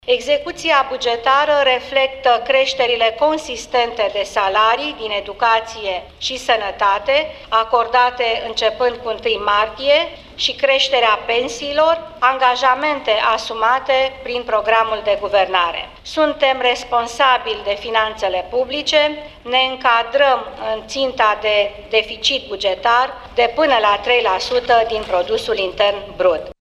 Ea a mai precizat, la începutul şedinţei executivului de astăzi, că România se încadrează în ţinta de deficit bugetar de până la 3 la sută din produsul intern brut.